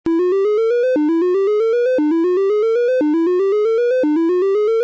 These were all recorded as WAV's and converted to MP3's to save space.